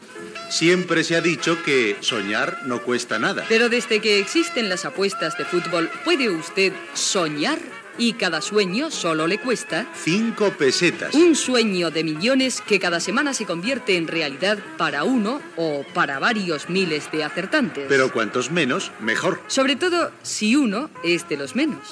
Diàleg ficcionat entre un apostant, don Celso, i el dependent d'un despatx d'administració de travesses, Ceferino.